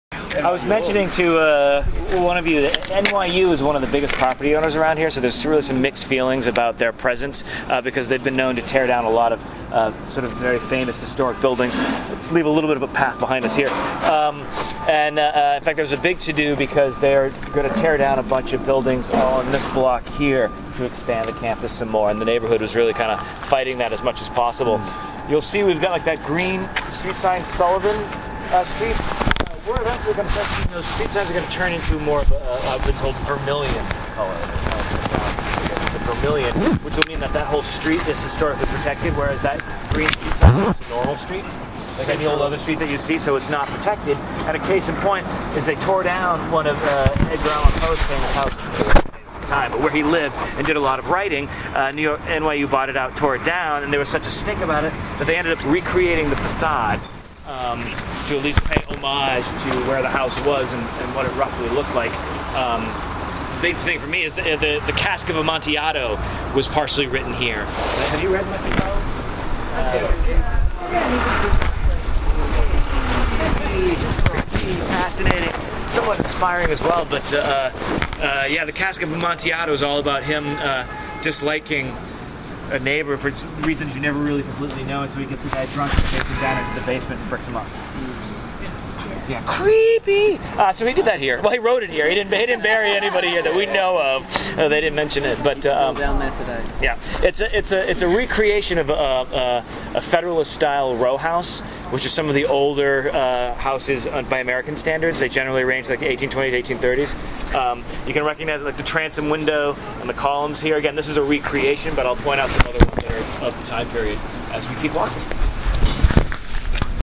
in situ!